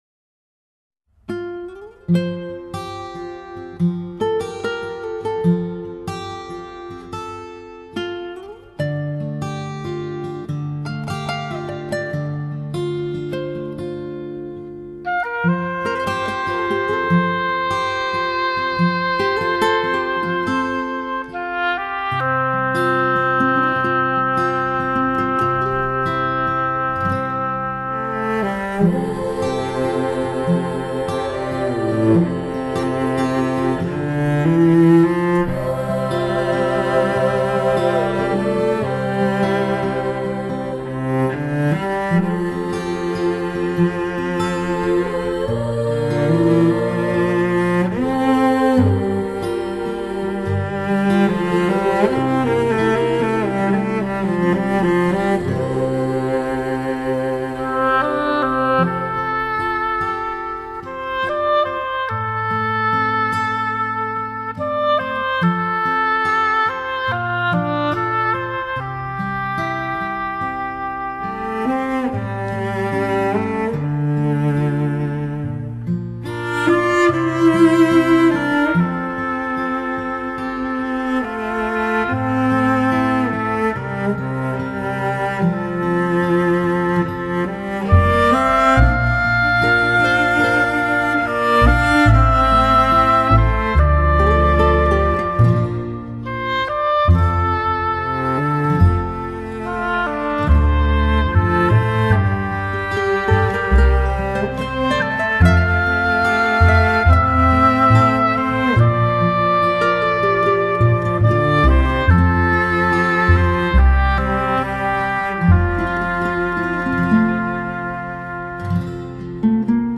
以中国民族乐器为主奏器乐，民族音调、
现代和声与配器，在编曲上旋律主题广阔，配器融合